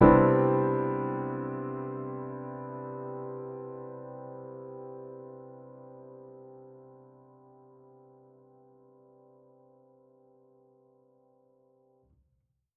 Index of /musicradar/jazz-keys-samples/Chord Hits/Acoustic Piano 1
JK_AcPiano1_Chord-Cmaj9.wav